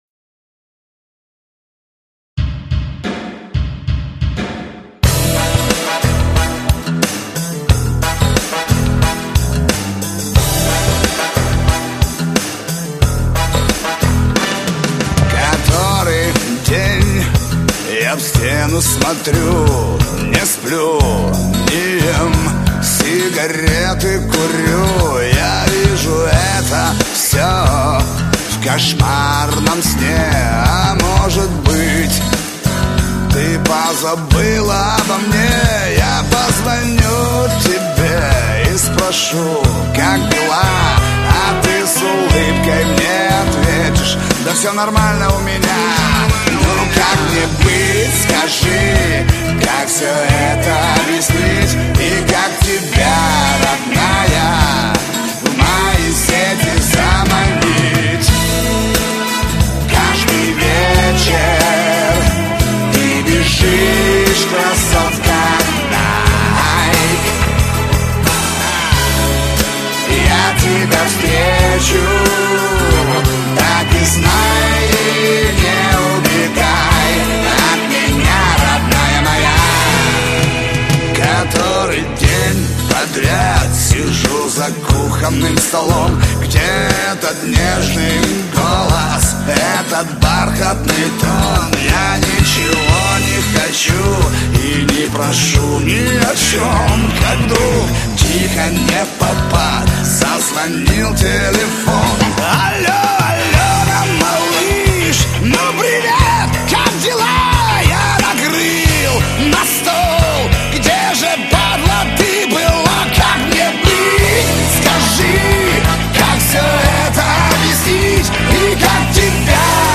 Эстрада, шансон